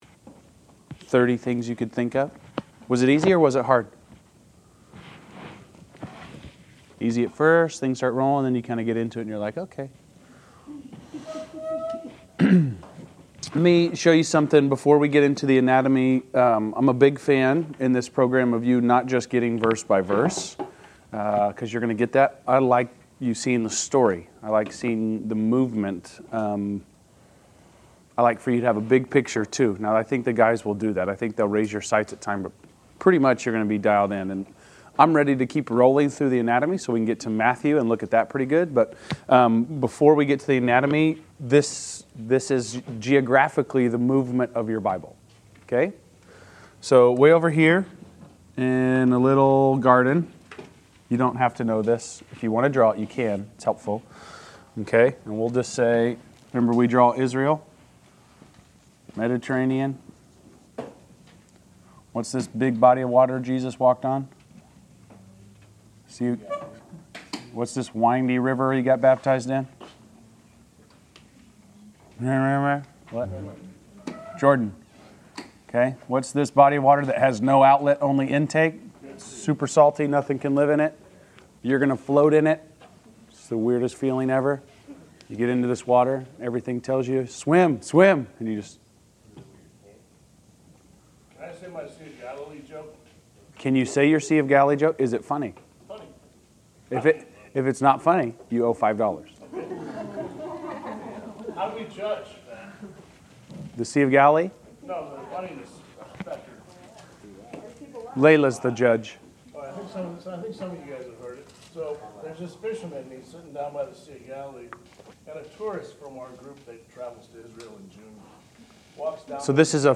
Class Session Audio September 24